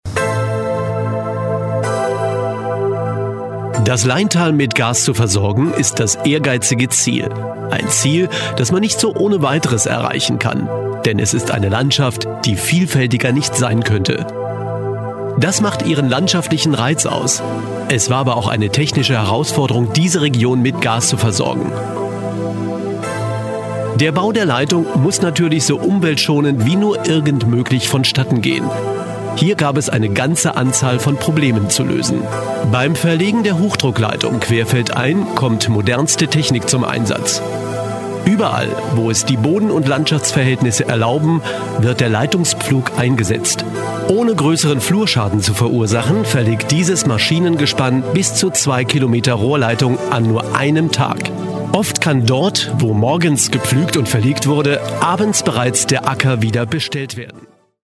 Deutscher Sprecher, Moderator, Stimmenimitator Thomas Gottschalk, ZDF, ARD, RTL2,Radio, Off-Sprecher, warme Stimme,Mittel-Stimme, Werbesprecher, Messe Industriefilm,
rheinisch
Sprechprobe: Industrie (Muttersprache):